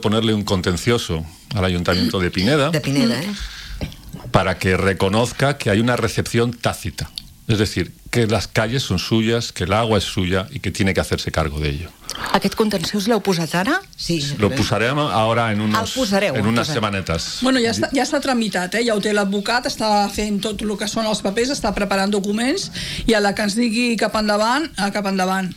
L’associació veïnal ha anunciat en una entrevista a RCT que, en les pròximes setmanes, presentarà un recurs contenciós administratiu per tal que el consistori reconegui la recepció tàcita de la urbanització i assumeixi el manteniment dels serveis bàsics, com ara l’asfaltatge, la neteja viària i l’enllumenat públic.